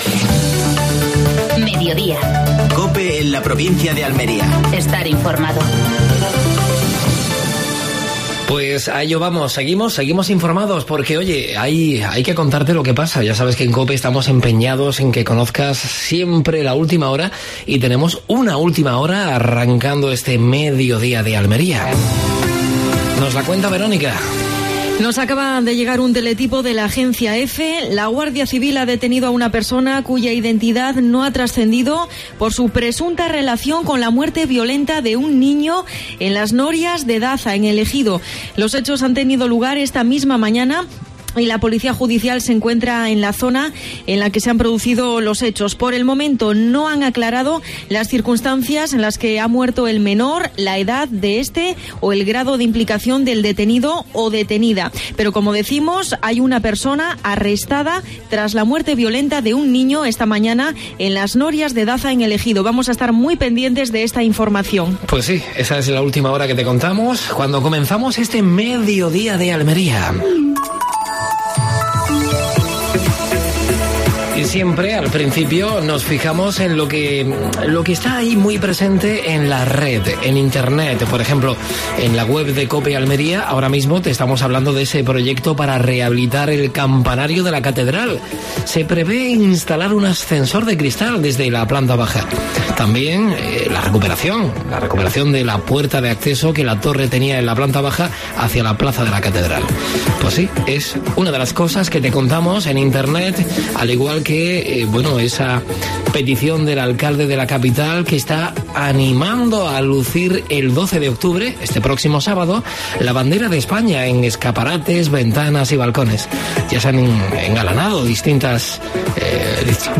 AUDIO: Actualidad en Almería. Entrevista a Javier Aureliano García (presidente Diputación Provincial de Almería).